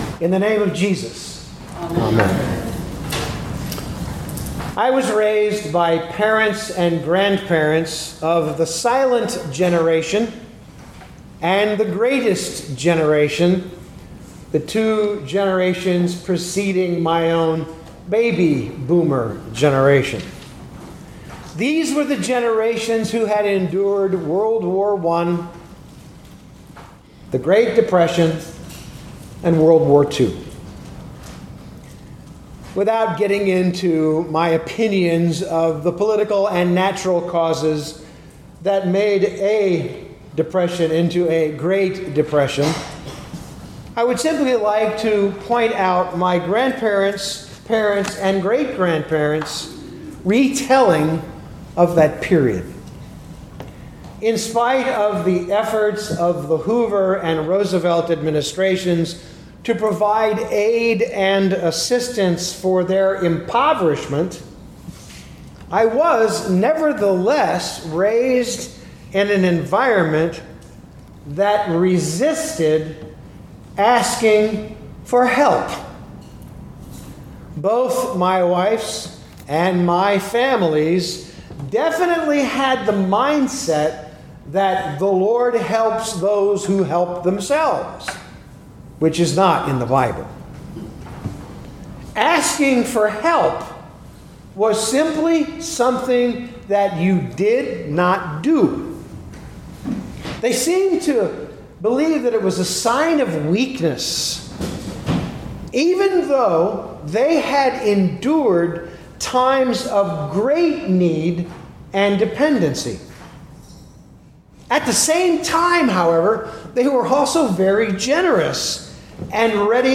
2024 Isaiah 50:4-10 Listen to the sermon with the player below, or, download the audio.